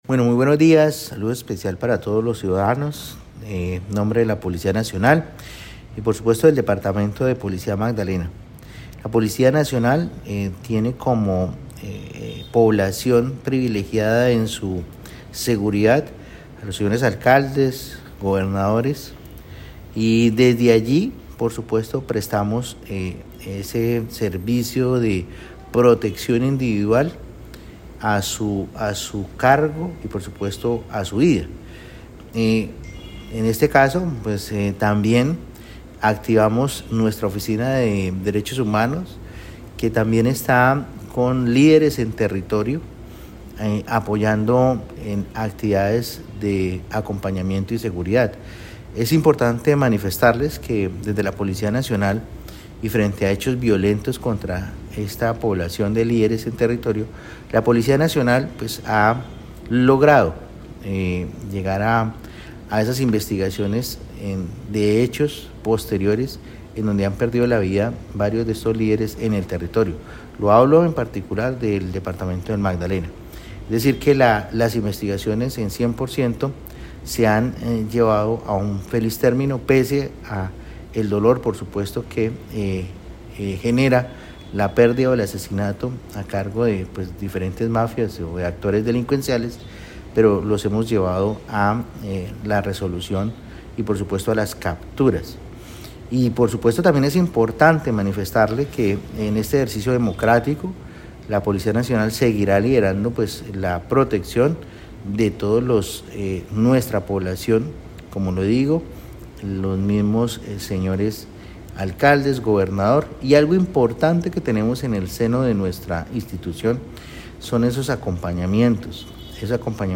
CORONEL JAVIER DUARTE, COMANDANTE POLICÍA MAGDALENA
Durante la socialización del plan, en diálogo con Caracol Radio, el coronel Duarte enfatizó la importancia de la denuncia oportuna y la articulación entre autoridades locales y la fuerza pública, como herramientas clave para contrarrestar posibles acciones criminales.